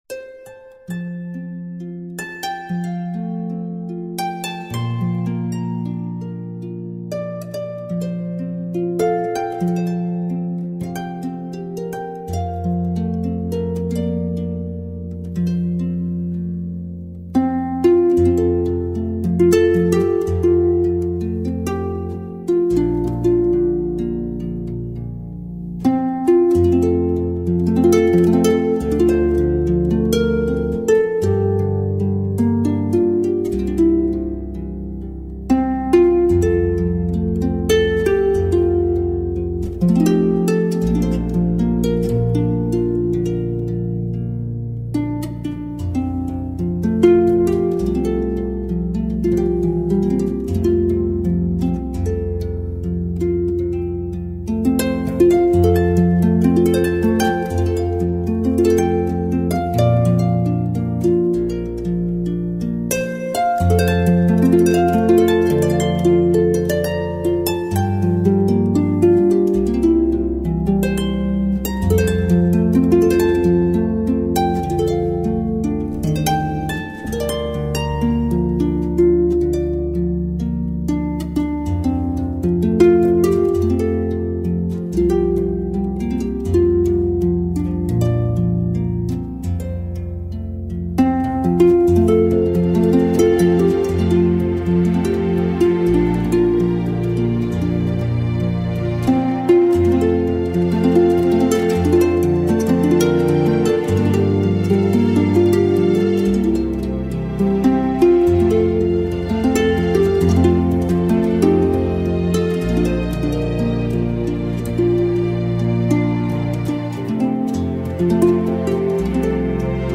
竖琴--红河谷